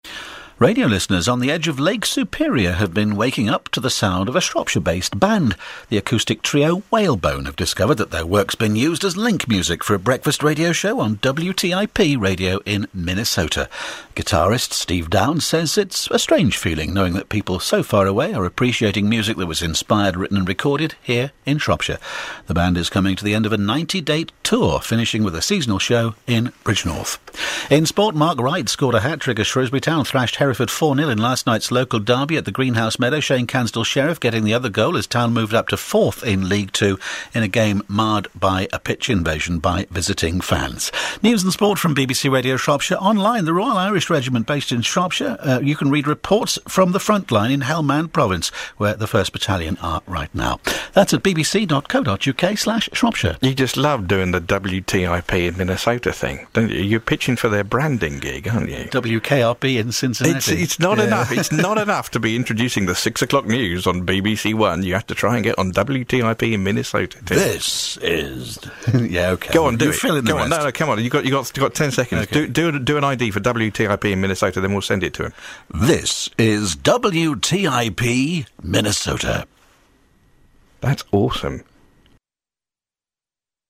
The story was picked up by the BBC in Shropshire and resulted in an on-air feature. Give it a listen Be sure to tune in all the way through, as WTIP gets a station ID from the BBC announcers at the end of the segment.